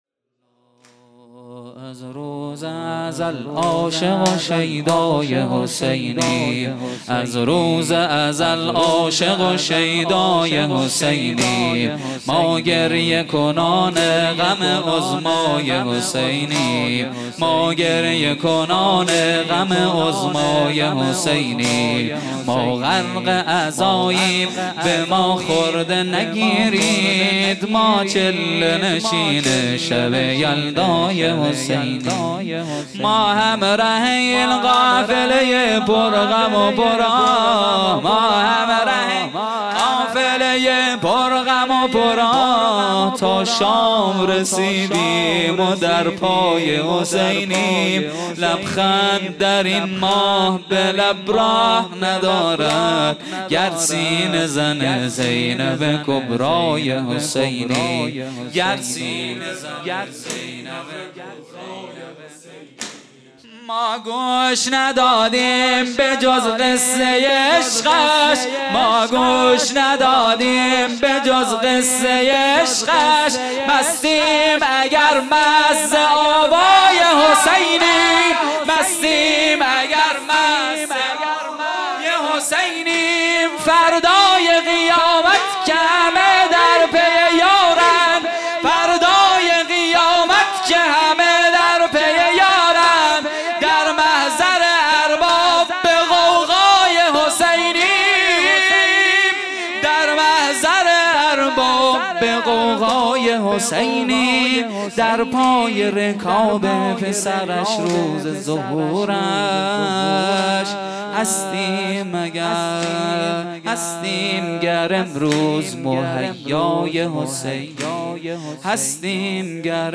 واحد | از روز ازل عاشق و شیدای حسینیم | پنج شنبه ۱۸ شهریور ۱۴۰۰
جلسه‌ هفتگی | شهادت حضرت رقیه(ع) | پنج شنبه ۱۸ شهریور ۱۴۰۰